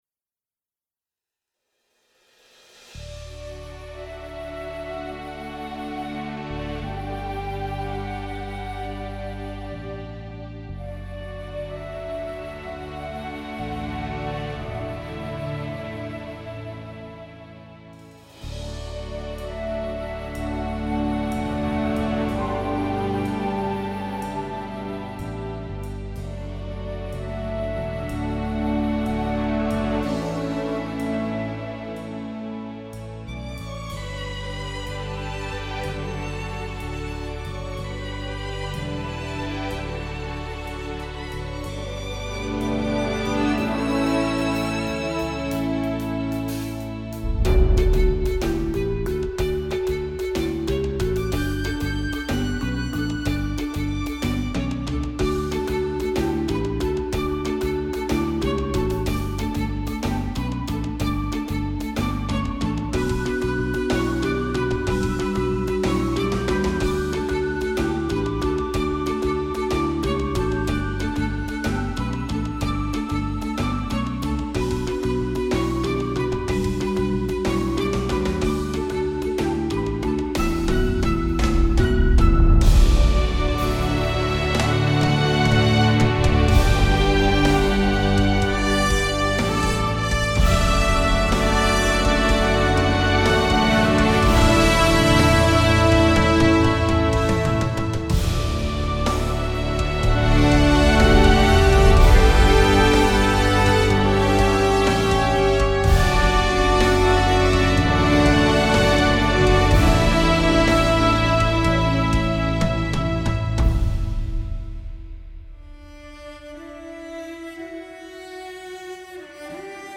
Partition Enregistrement (instrumental)
instrumental.mp3